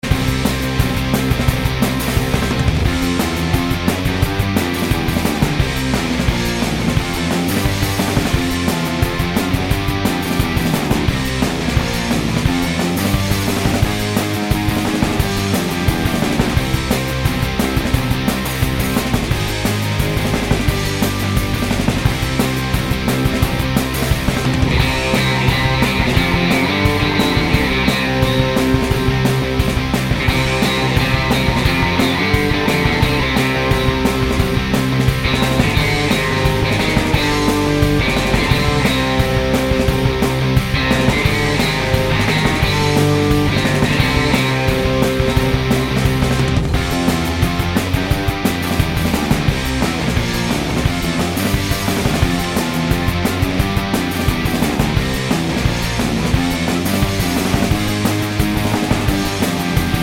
no Backing Vocals Punk 2:54 Buy £1.50